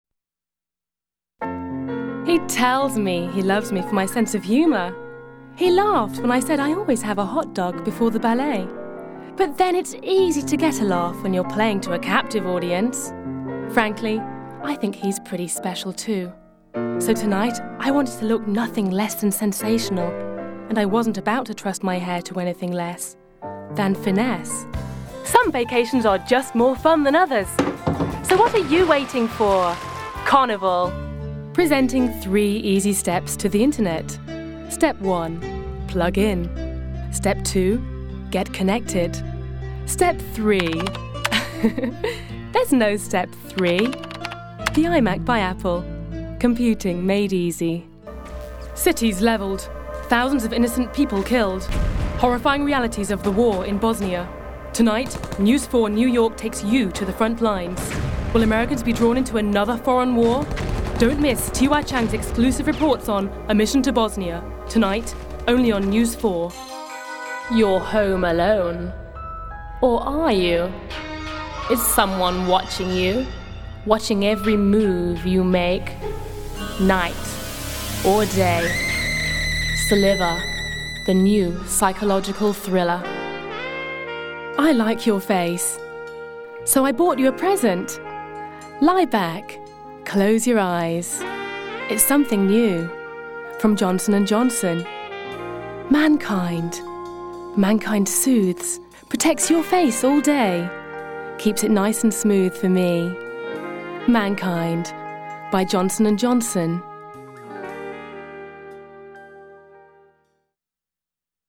Voice Over, British Female
VOICE REEL